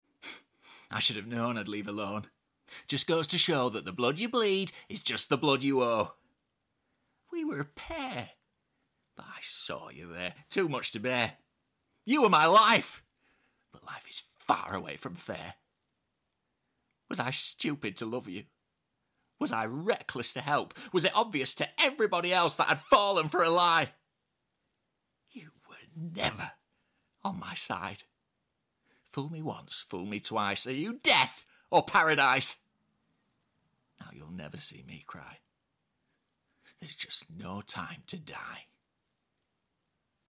🎭 Dramatic Monologue: No Time Sound Effects Free Download